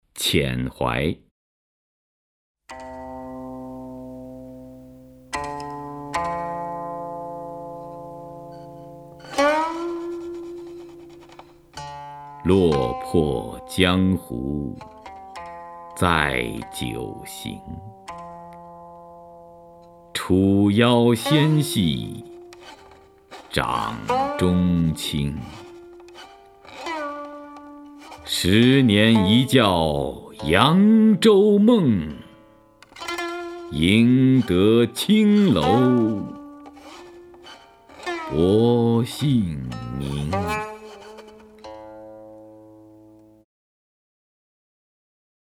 徐涛朗诵：《遣怀》(（唐）杜牧) （唐）杜牧 名家朗诵欣赏徐涛 语文PLUS